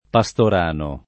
[ pa S tor # no ]